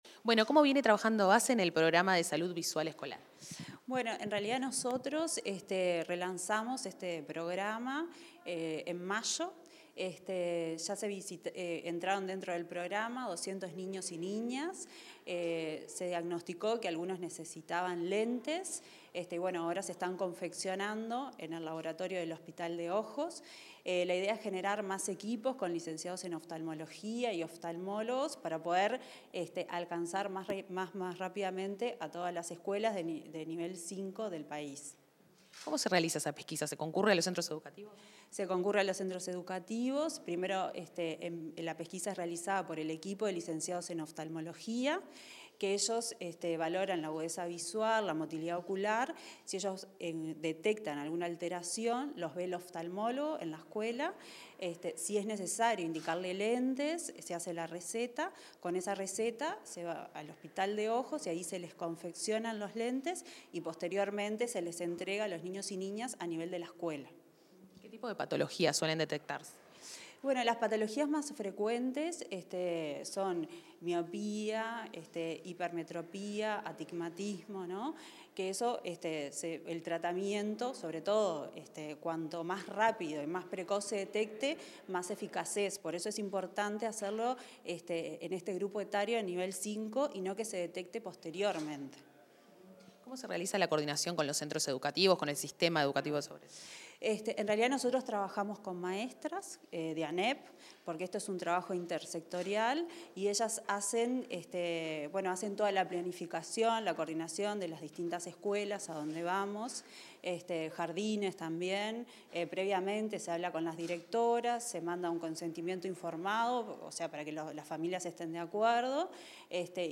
Declaraciones de la directora de Salud de Niñez y Adolescencia de ASSE, Stefanía Cabo